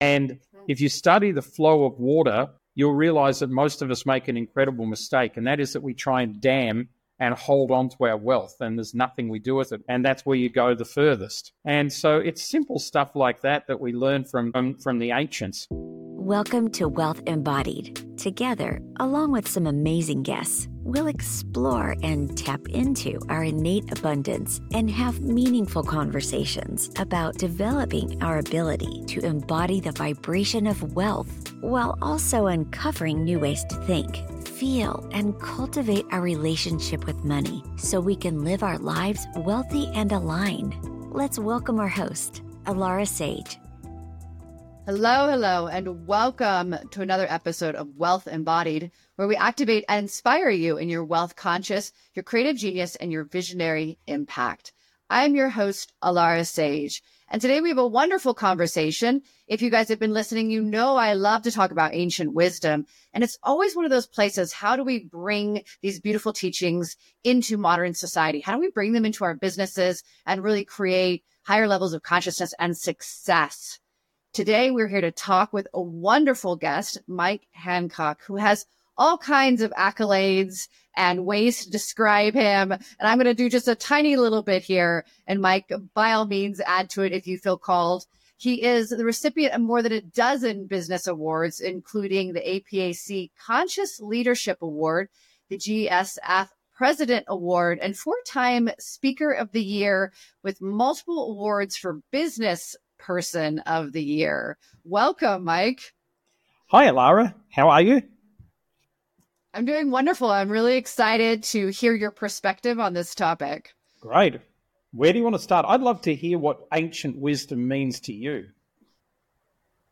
Conversations and guest interviews to help you learn how to activate wealth consciousness, embody the frequency of wealth and apply financial strategies to your life. Listen to compelling conversations and insights on Quantum Mechanics, the Law of Attraction, Manifestation, and Wealth Creation and Management.